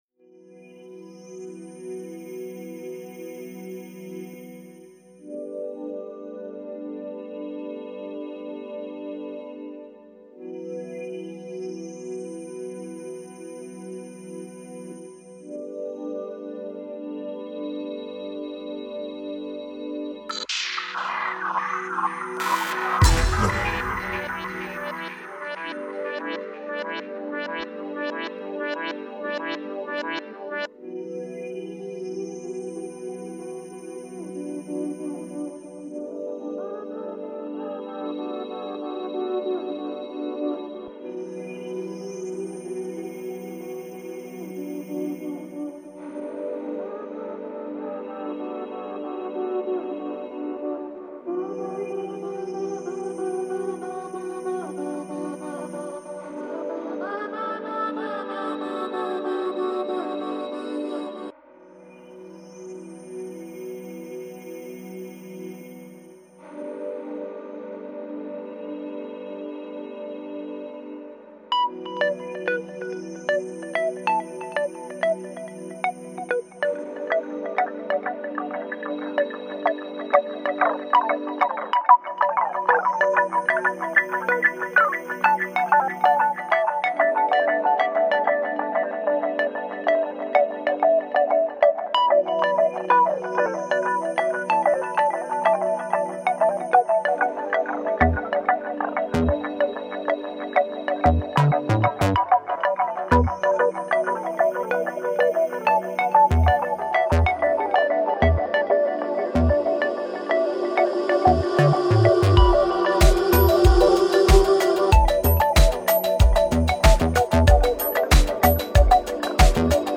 BlackPlastic struggles with ambient.